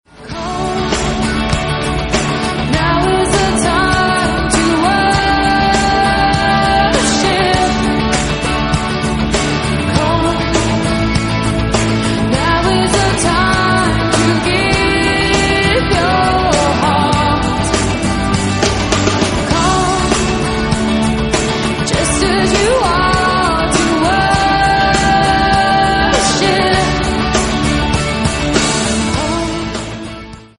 erstklassigen Live-Aufnahme
• Sachgebiet: Praise & Worship